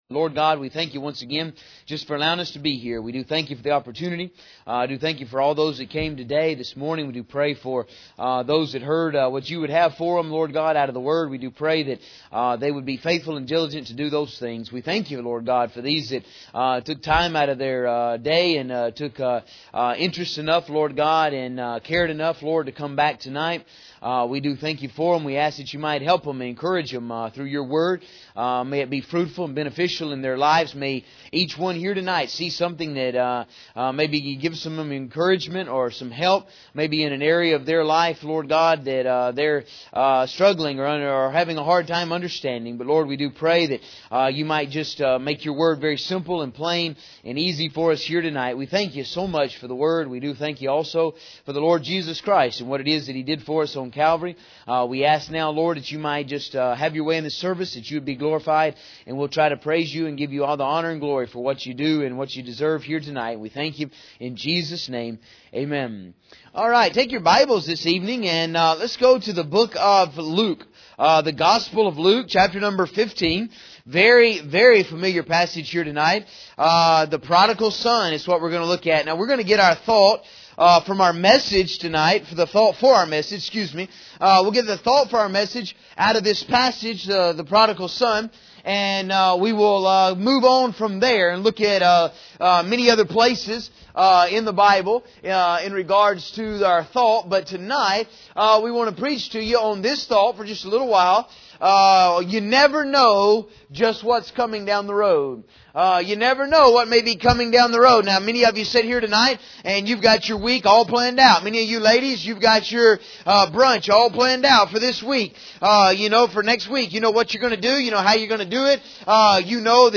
This sermon deals with the story of the prodigal son and his return.